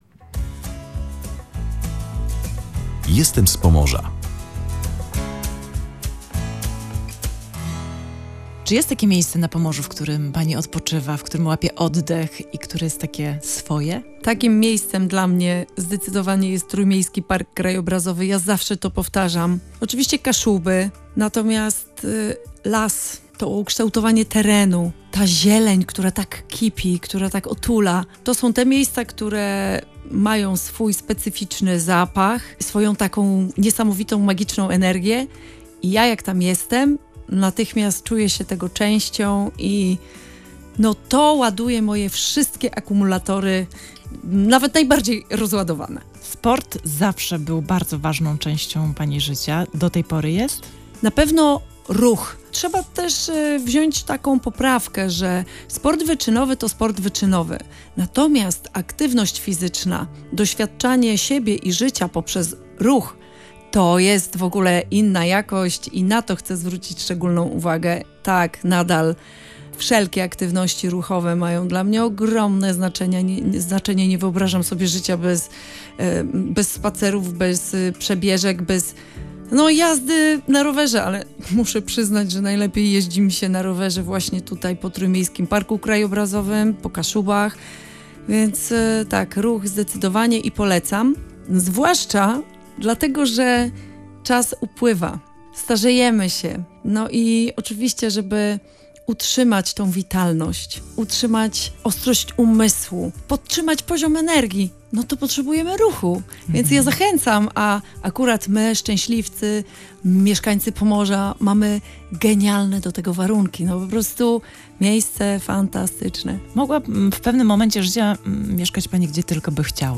Bohaterką audycji „Jestem z Pomorza” była Iwona Guzowska.